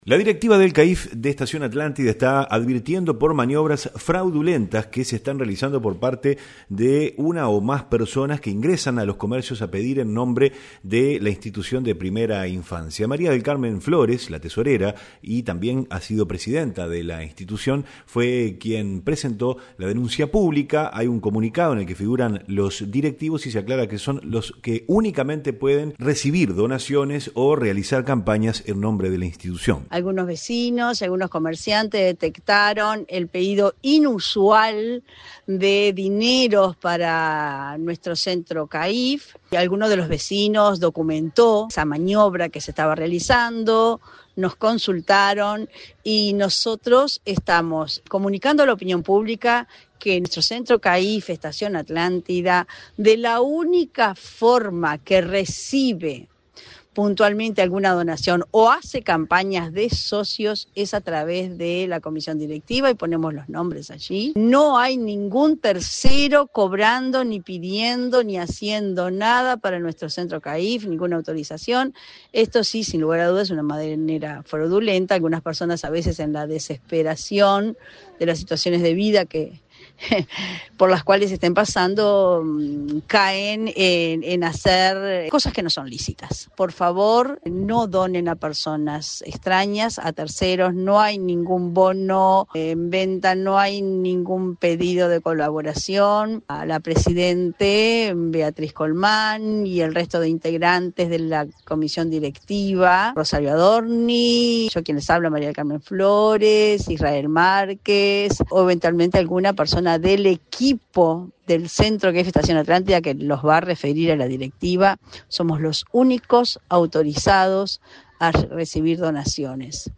REPORTE-CAIF.mp3